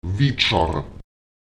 Lautsprecher wejar [ÈwetSar] höchstwahrscheinlich